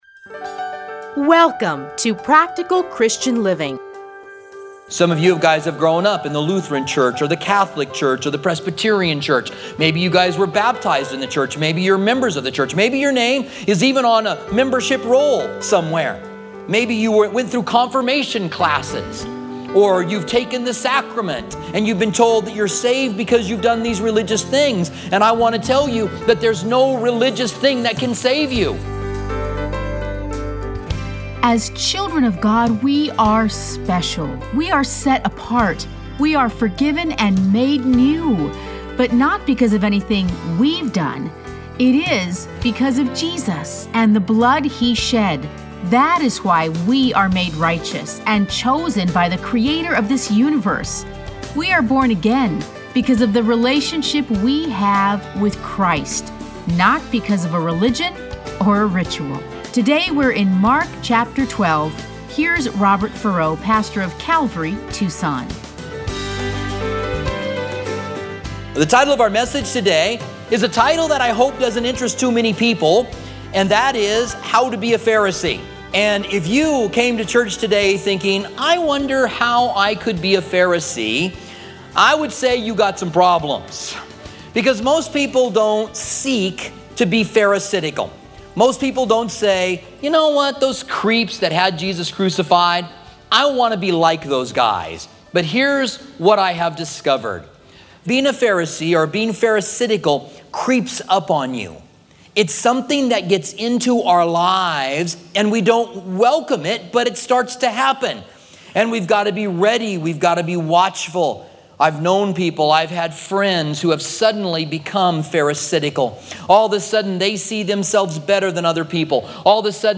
Listen to a teaching from Mark 12.